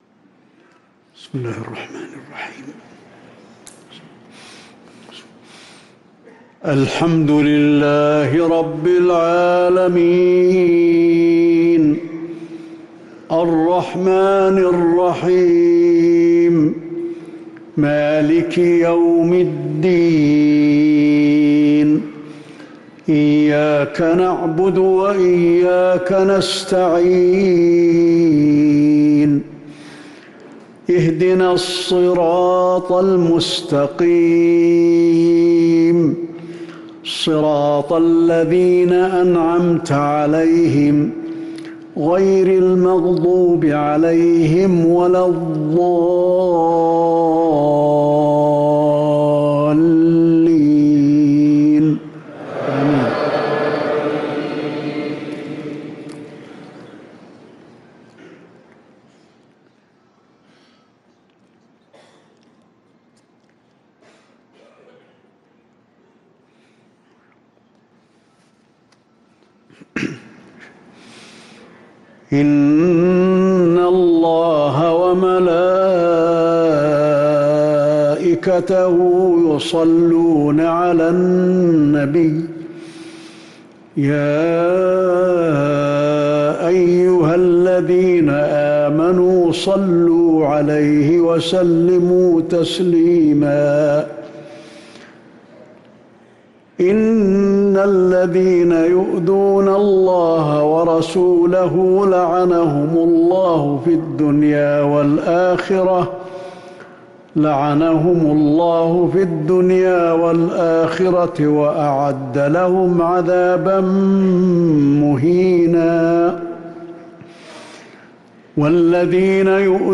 صلاة العشاء للقارئ علي الحذيفي 14 شوال 1444 هـ
تِلَاوَات الْحَرَمَيْن .